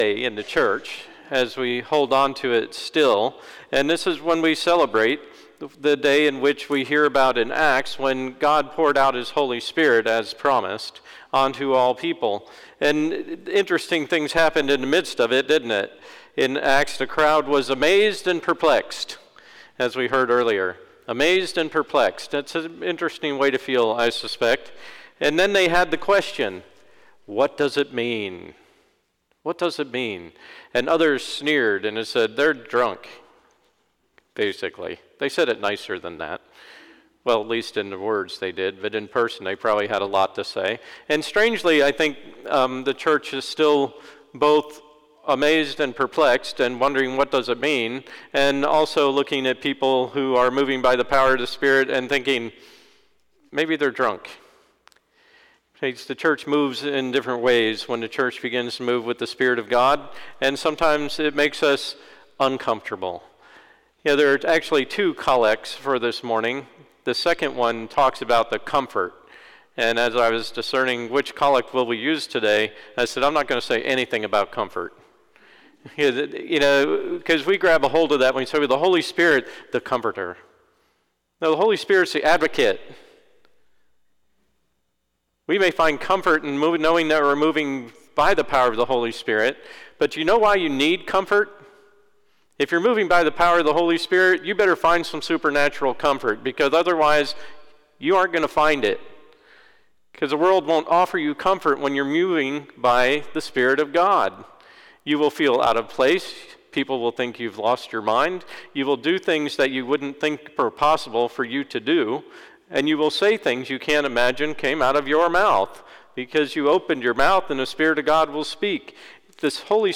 Sermon 6/5/2022, Day of Pentecost